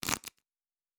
Cards Shuffle 1_04.wav